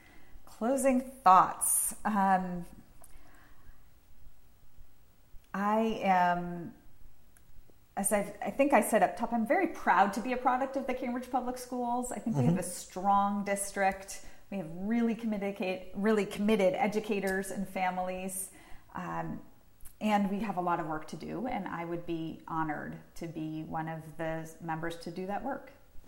Q&A